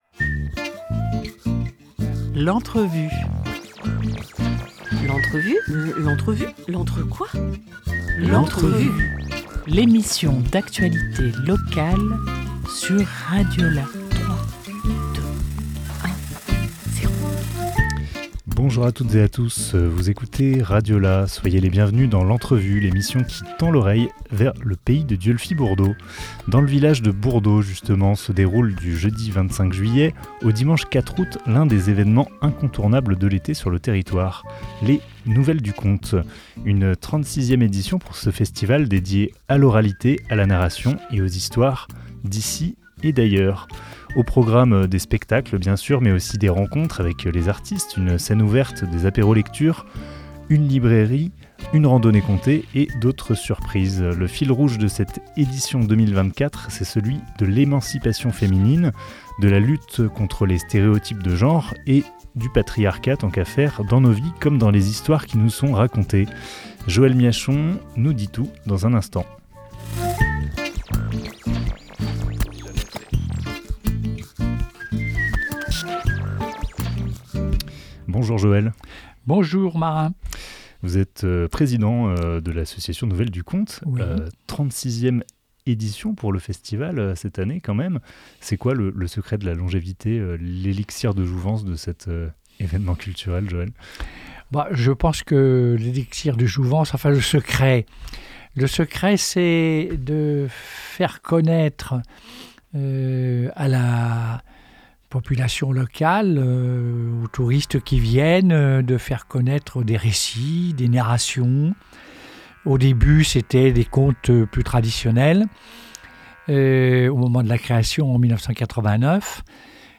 16 juillet 2024 11:18 | Interview